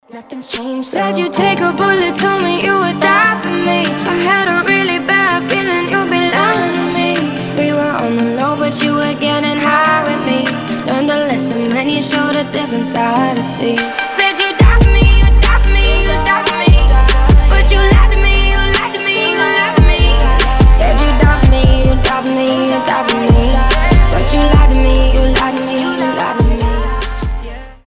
красивый женский голос